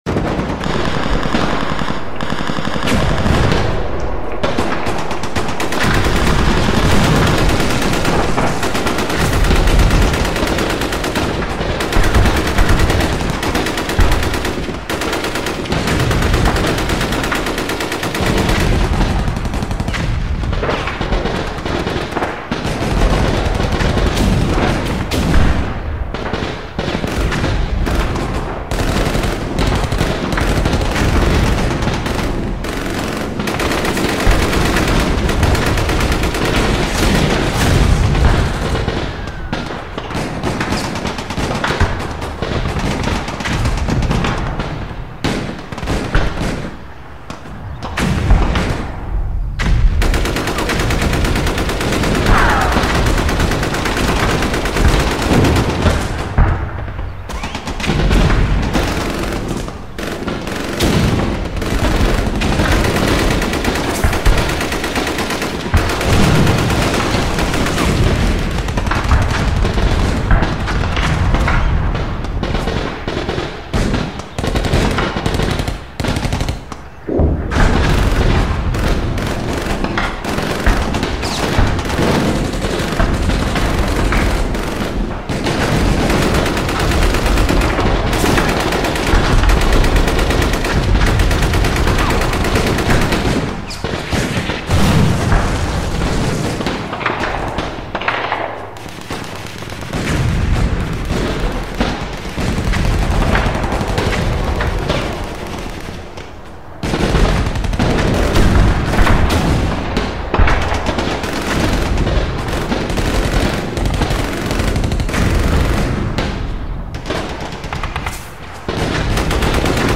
جلوه های صوتی
دانلود صدای جنگ 2 از ساعد نیوز با لینک مستقیم و کیفیت بالا